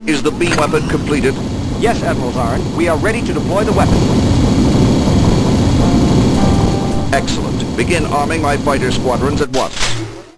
―Admiral Zaarin and an underling — (audio)